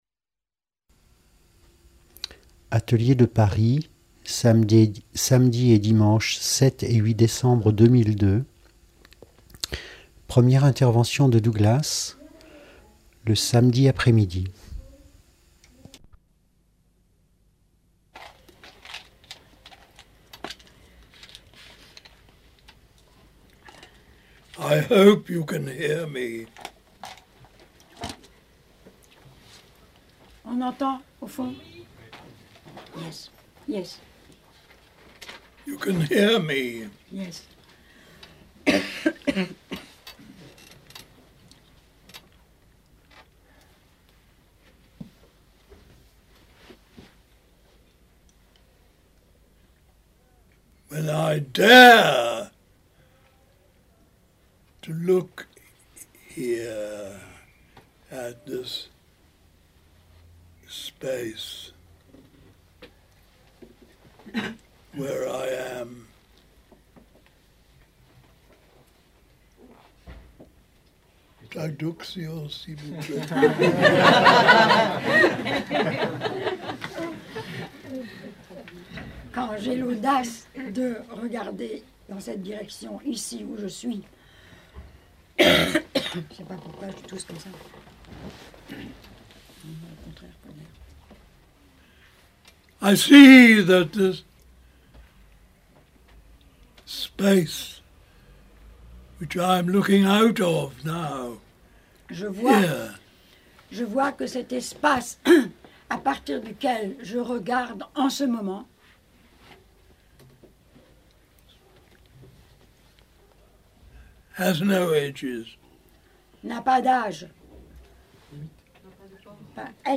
Je suis toujours très touché d'entendre la voix grave de cet homme de 92 ans qui a consacré toute sa vie à trouver et partager de précieux moyens pour permettre à chacun de voir sa véritable nature.